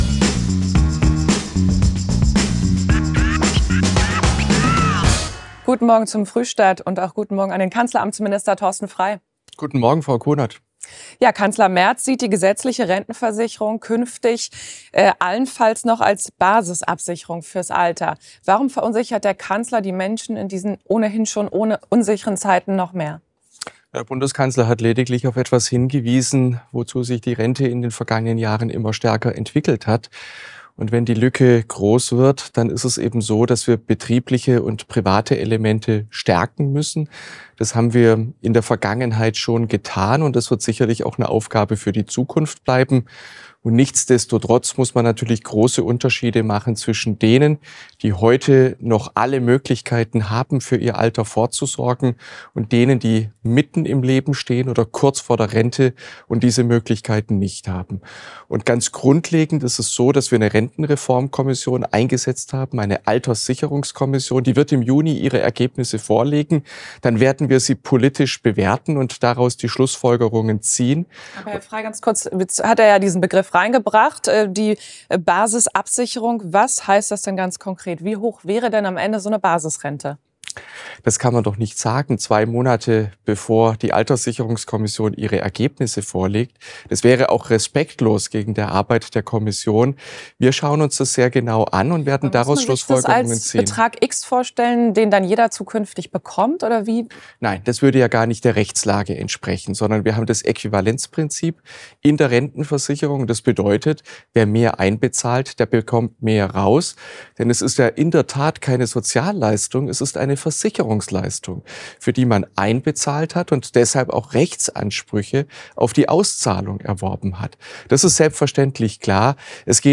Kanzleramtsminister Thorsten Frei zeigt sich im ntv Frühstart